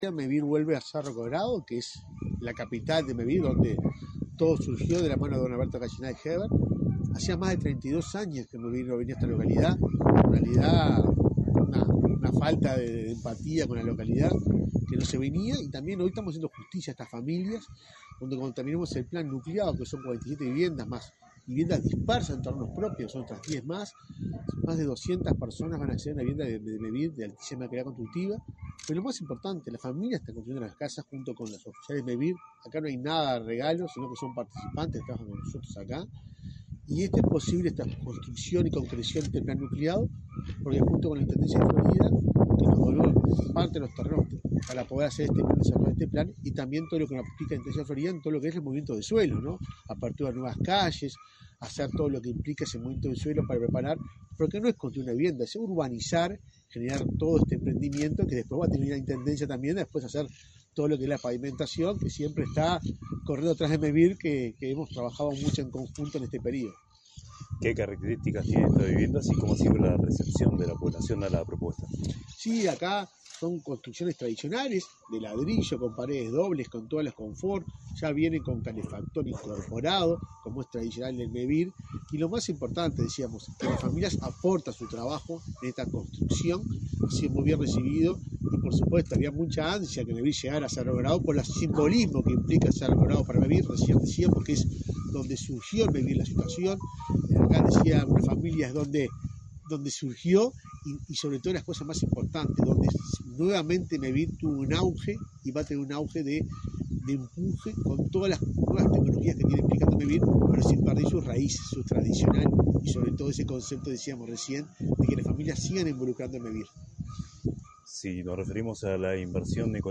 Entrevista al presidente de Mevir, Juan Pablo Delgado
Entrevista al presidente de Mevir, Juan Pablo Delgado 26/09/2024 Compartir Facebook X Copiar enlace WhatsApp LinkedIn El presidente de Mevir, Juan Pablo Delgado, dialogó con Comunicación Presidencial en Florida, durante una recorrida que realizó, este jueves 26, por las obras de un plan de 47 viviendas nucleadas en la localidad de Cerro Colorado.